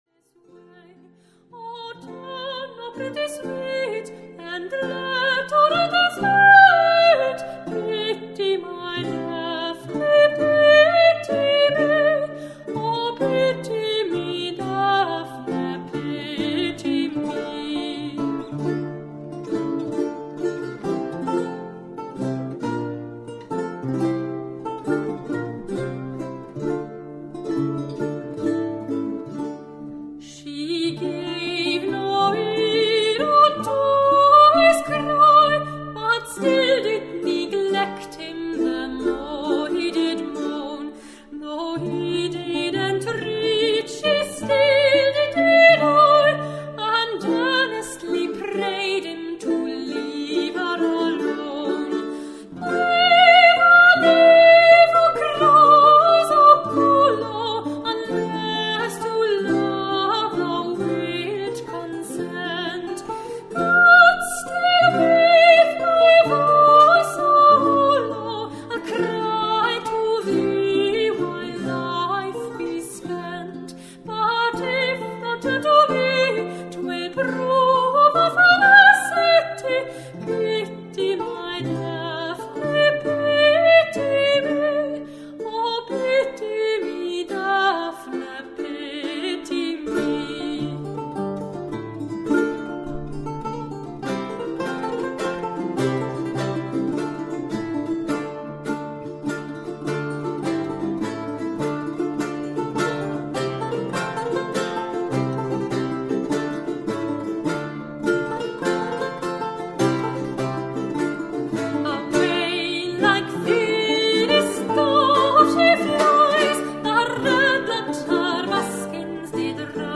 Singing
Broadside Ballad
performed by Renaissance Ensemble Pantagruel